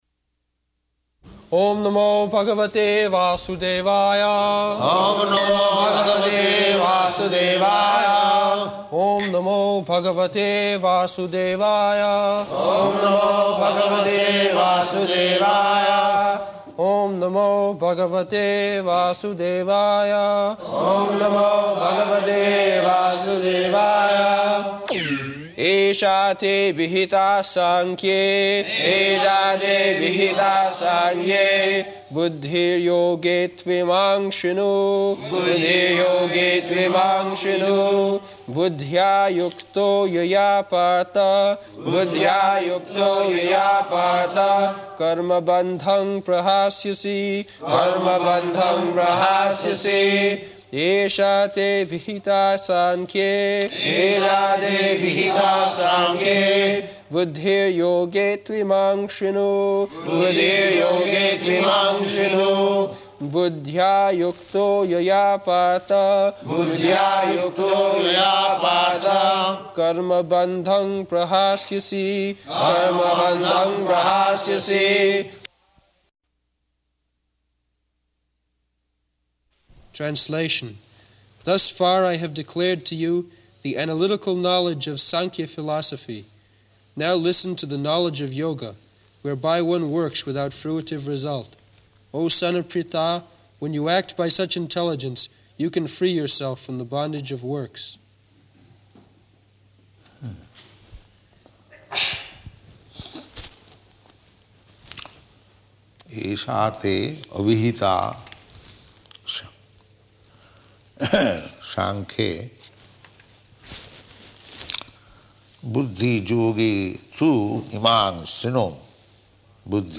September 12th 1973 Location: London Audio file
[Prabhupāda and devotees repeat]